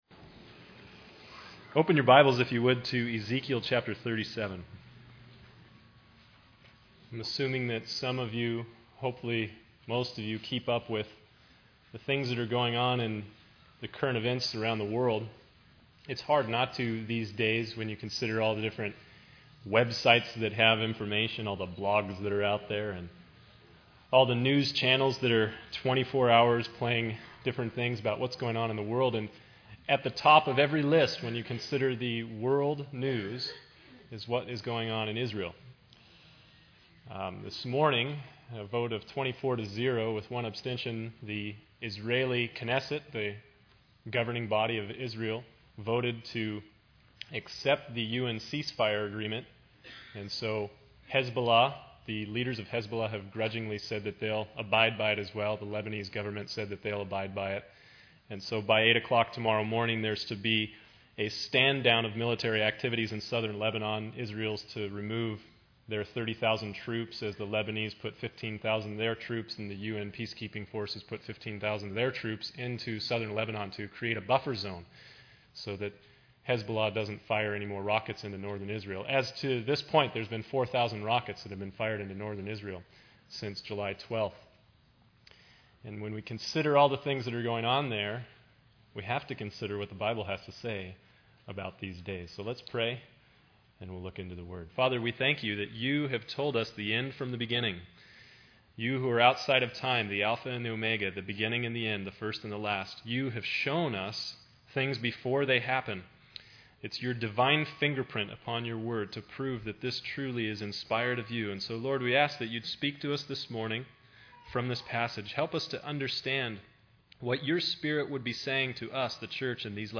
In this sermon, the speaker emphasizes the importance of knowing the time and being aware of the nearness of salvation. He urges the church to cast off the works of darkness and put on the armor of light, walking honestly and avoiding sinful behaviors.